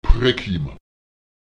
Lautsprecher prekem [ČprEkem] sterben (tot werden)